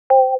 duoSynth.wav